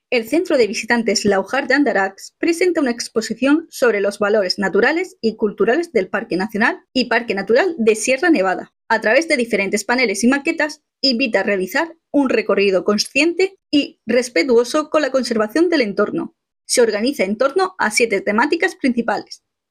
Exposición (Folleto locutado)